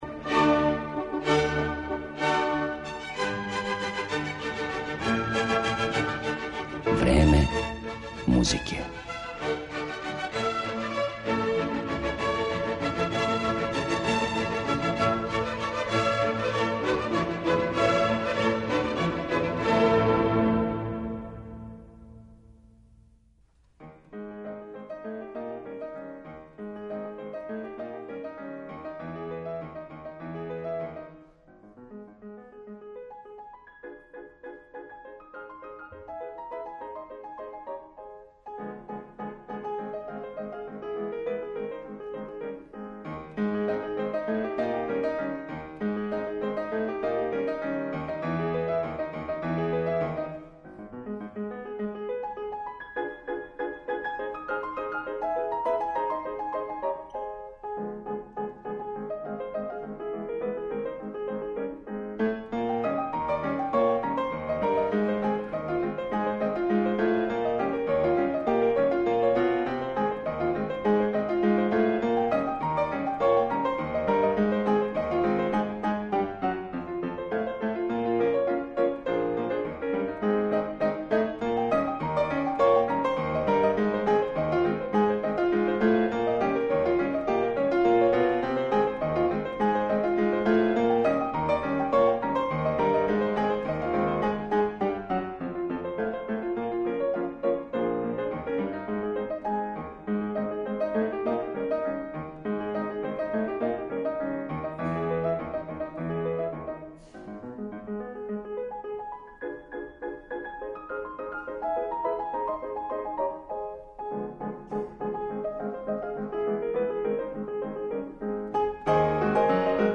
Ово су речи једног од највећих британских пијаниста данашњице, Стивена Озборна, музичара чији ћемо уметнички лик представити у данашњем Времену музике, кроз његове интерпретације дела Скота Џоплина, Мориса Равела, Сергеја Рахмањинова и Џорџа Гершвина.